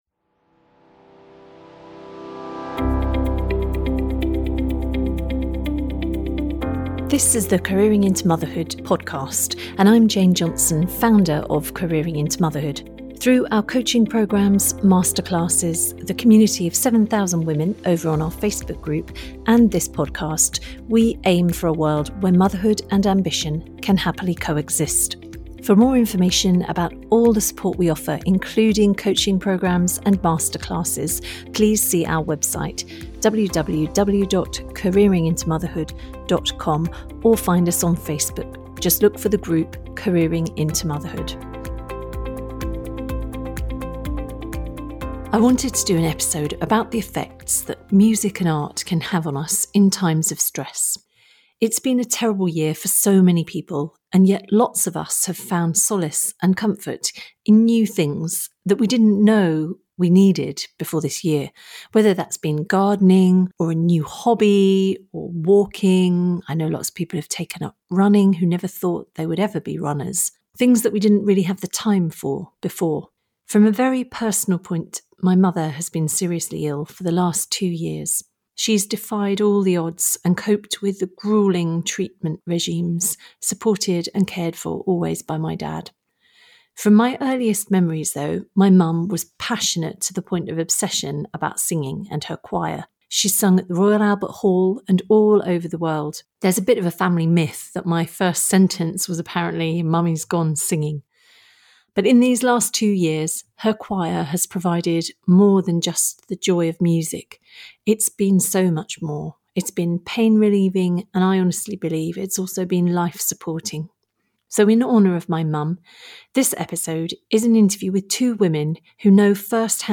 In two very different interviews